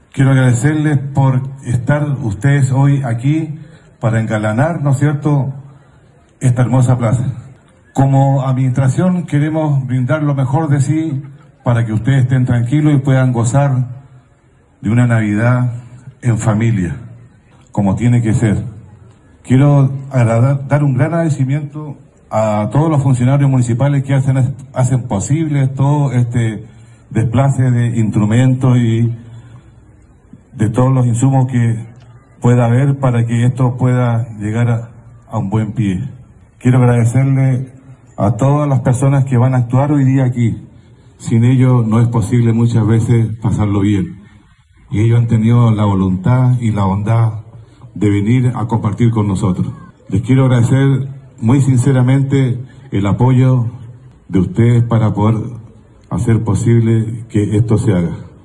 En su discurso, la máxima autoridad comunal expresó su agradecimiento por hacer posible esta celebración, destacando el compromiso de la administración por brindar espacios de unión familiar.
CUNA-ALCALDE-BALTAZAR-ARBOL-NAVIDAD.mp3